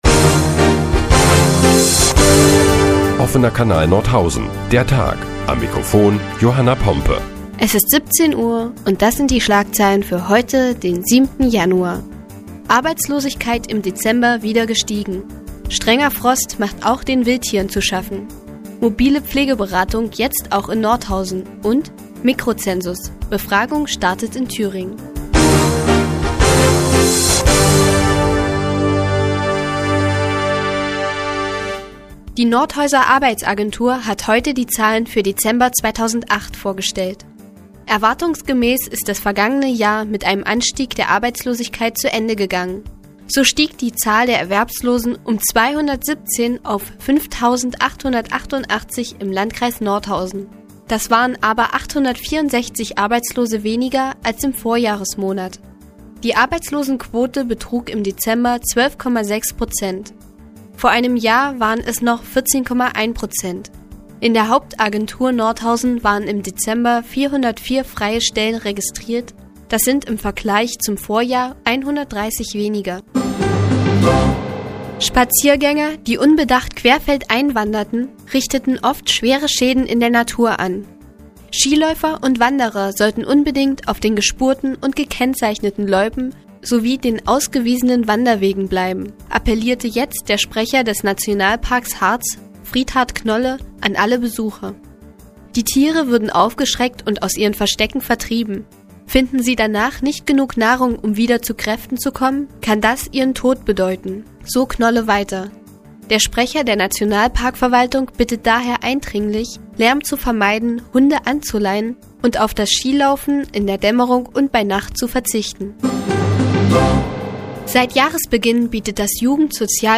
Die tägliche Nachrichtensendung des OKN ist nun auch in der nnz zu hören. Heute geht es unter anderem um die Arbeitslosenzahlen in Nordhausen und um Schäden im Nationalpark Harz.